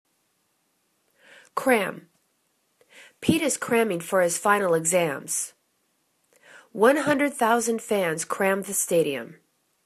cram     /kram/    v